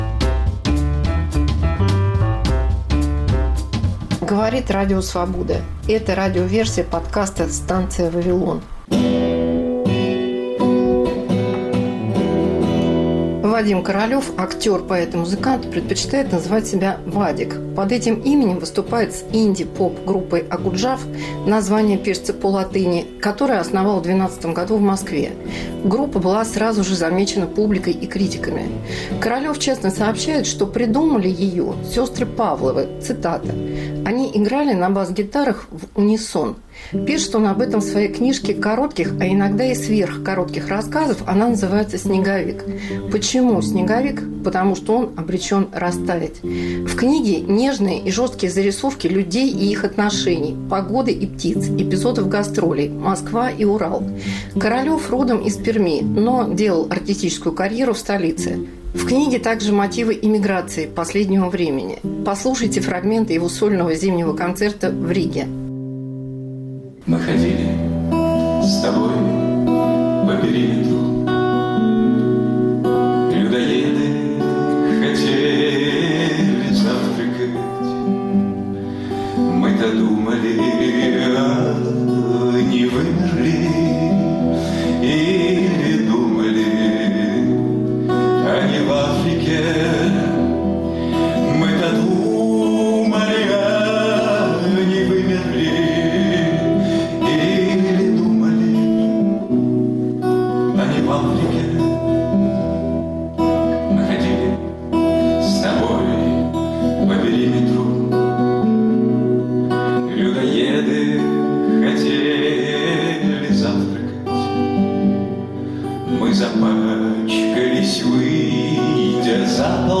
Новогодний концерт